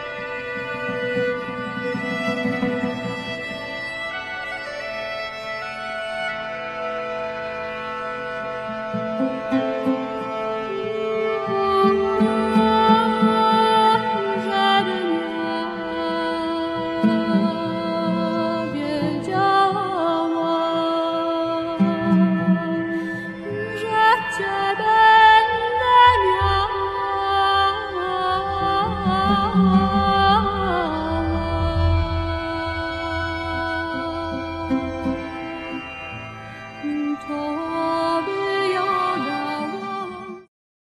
darabuka, tombak, udu